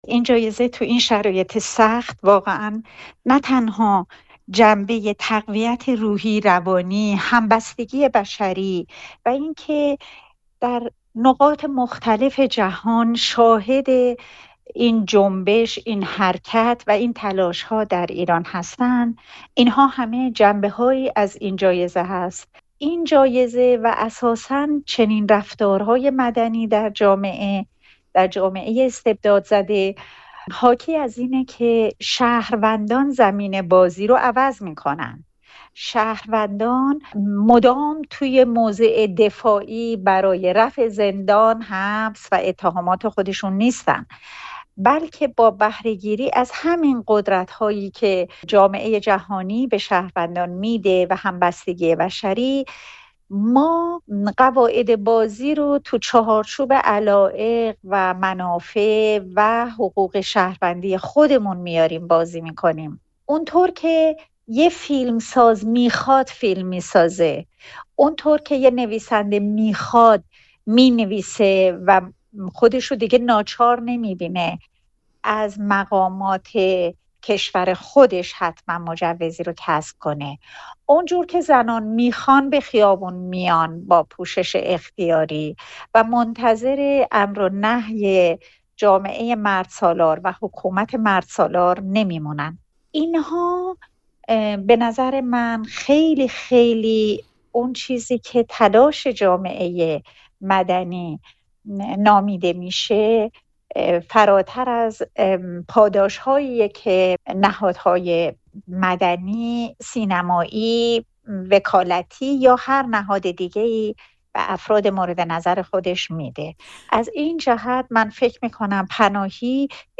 تاثیر جایزهٔ جعفر پناهی بر جامعه مدنی ایران در گفت‌وگو با نسرین ستوده